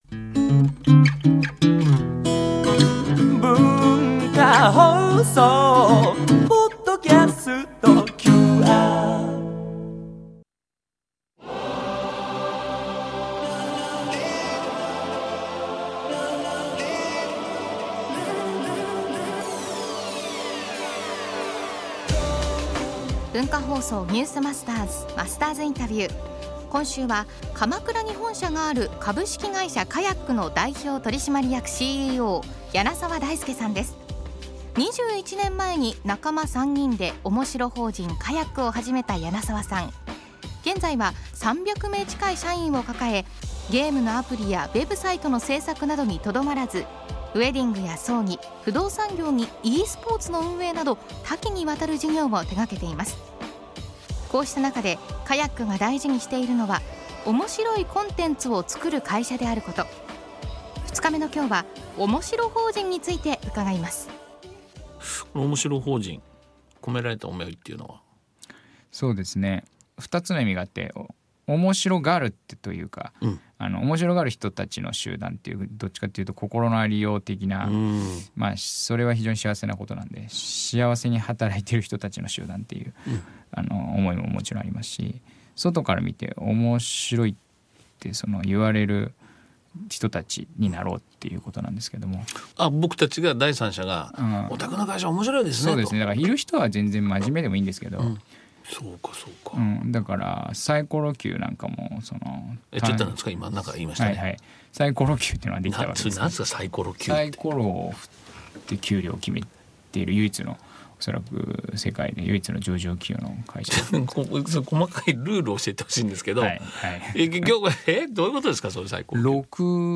毎週、現代の日本を牽引するビジネスリーダーの方々から次世代につながる様々なエピソードを伺っているマスターズインタビュー。